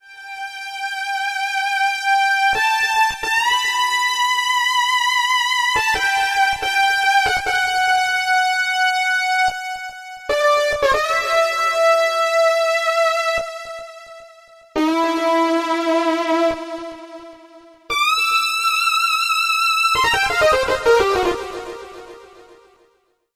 ROLAND D-50
Il offre ici des sonorités nouvelles basées sur une synthèse soustractive numérique et des attaques à base d'échantillions PCM.
Très puissant, très rock, le D-50 sera mis à grosse contribution dans l'album Révolutions comme l'a fait le Synthex dans Rendez-Vous.
Analog Chop